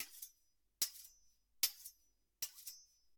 Afilando un cuchillo
Cocina - Zona de preelaboración
Sonidos: Acciones humanas